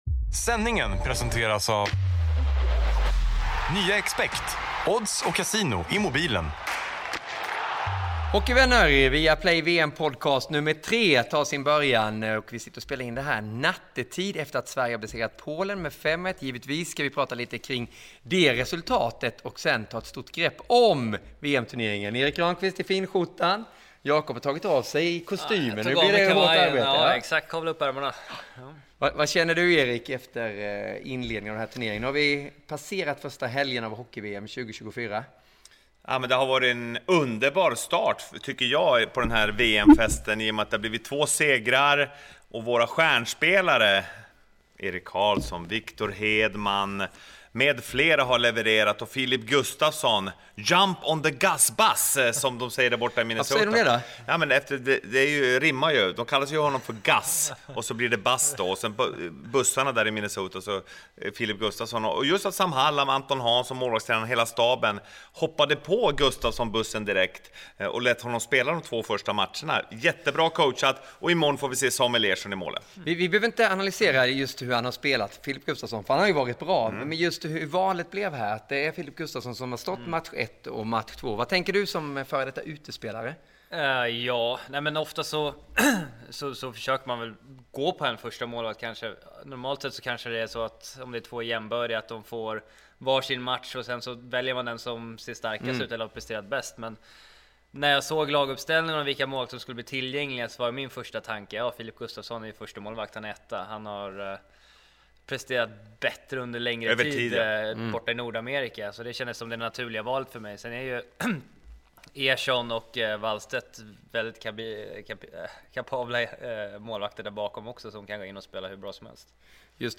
Intervjuer och analyser.